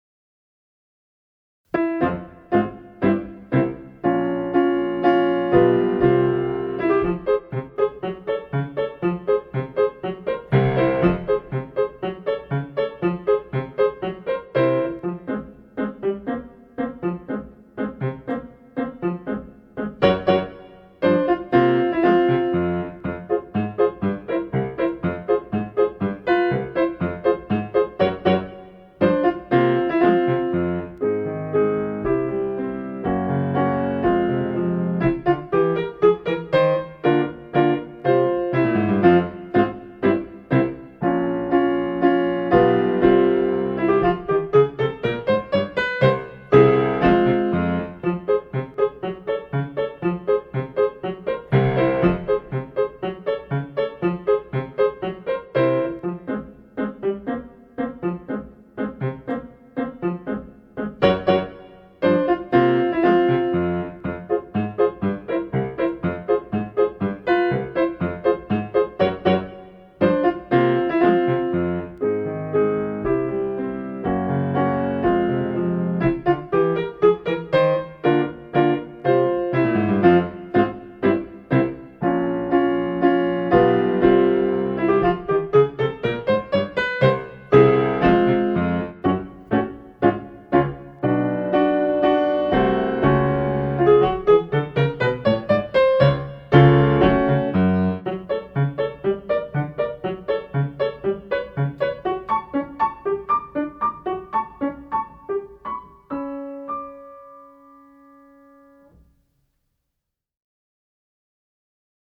【Instrumental / リマスター版2025】 mp3 DL ♪